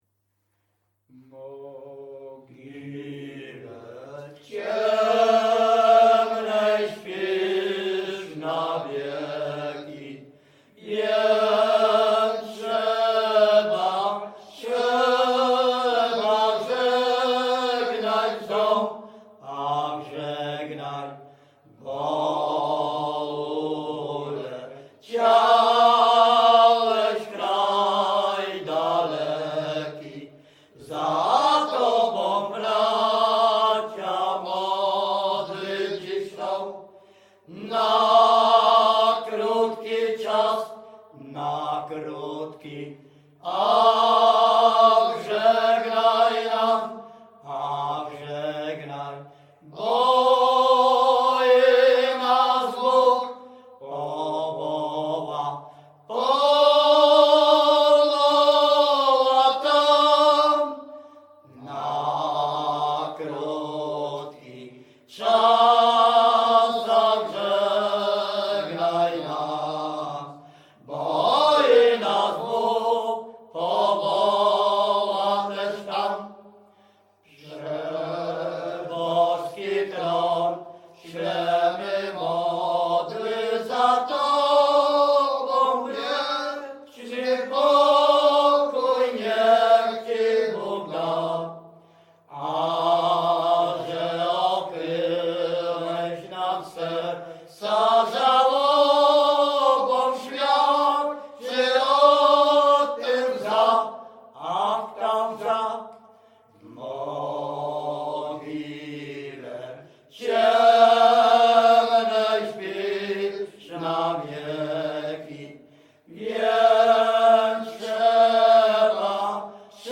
Śpiewacy z Ruszkowa Pierwszego
Wielkopolska, powiat kolski, gmina Kościelec, wieś Ruszków Pierwszy
Pogrzebowa
Array nabożne katolickie pogrzebowe